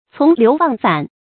從流忘反 注音： ㄘㄨㄙˊ ㄌㄧㄨˊ ㄨㄤˋ ㄈㄢˇ 讀音讀法： 意思解釋： 謂習從流俗而不可扭轉。